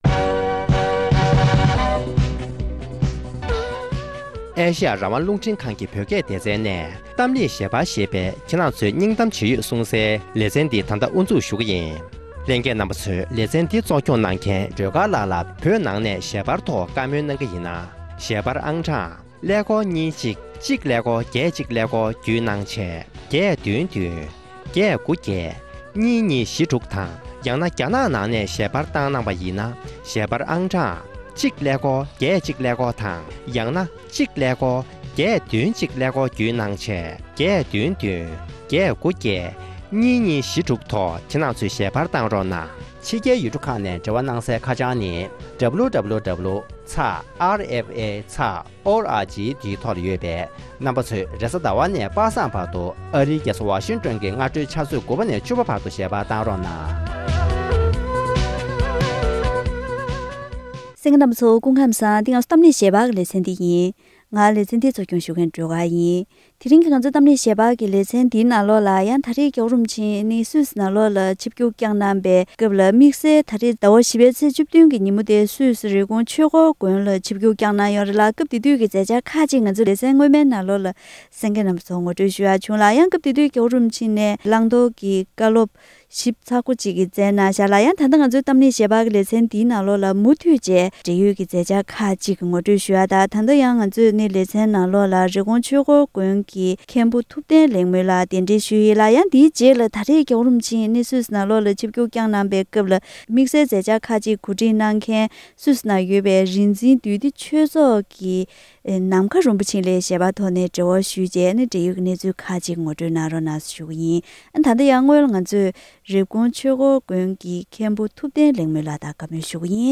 ༧གོང་ས་༧སྐྱབས་མགོན་ཆེན་པོ་མཆོག་ནས་སུད་སི་ནང་ཡོད་པའི་བོད་པའི་གཞོན་སྐྱེས་ཚོར་ནང་ཆོས་སློབ་གཉེར་དང་བོད་སྐད་ཡིག་སློབ་སྦྱོང་གནང་ཕྱོགས་ཐད་དམིགས་བསལ་བཀའ་སློབ་གནང་ཡོད་པ་རེད།